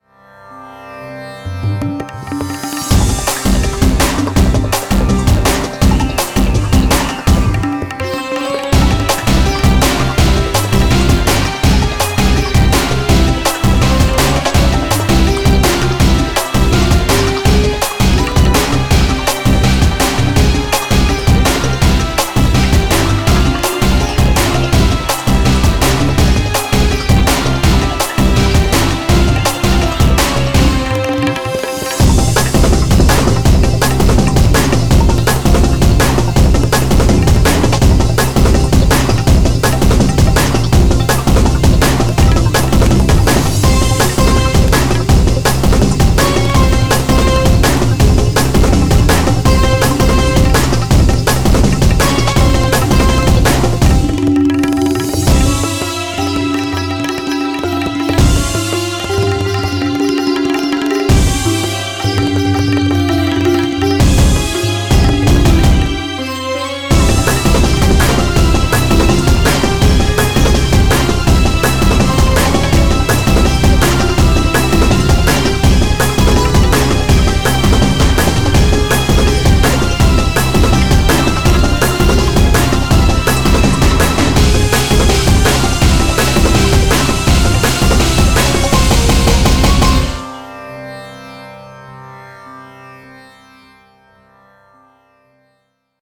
BPM165
MP3 QualityMusic Cut